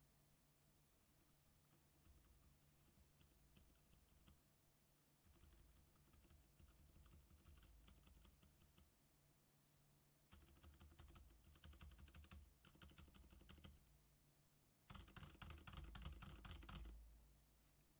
そうやって苦労して入れたFrozen Silent V2だけど、音は41~46dBくらい。
Frozen Silent V2は極めて静か
ラップトップのマイクで拾うと机を叩くわずかな「コッ」ていう音のほうが響く。
同じマイクセッティングで同じように打鍵したデータを用意した。ゲイン調整もしていない。
Frozen Silent V2の打鍵音